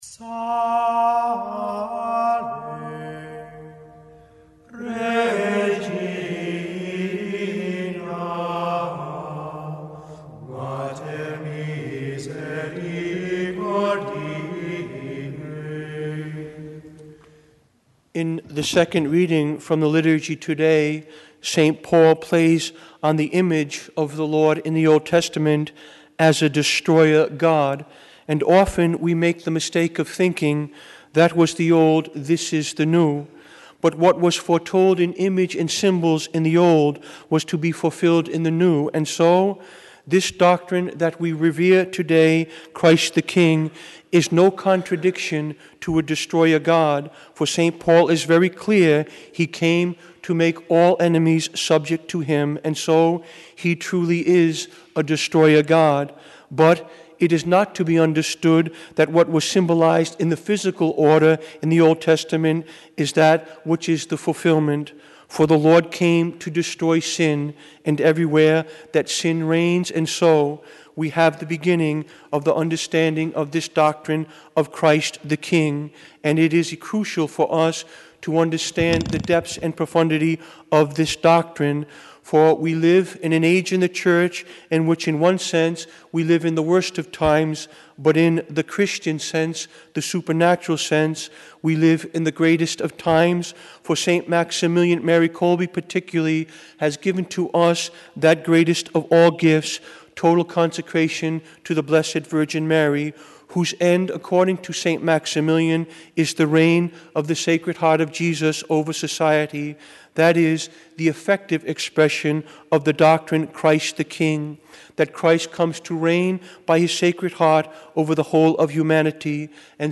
Homily
Mass: Christ the King - Solemnity - Form: OF Readings: 1st: eze 34:11-12, 15-17 Resp: psa 23:1-2, 2-3, 5, 6 2nd: 1co 15:20-26, 28 Gsp: mat 25:31-46 Audio (MP3) +++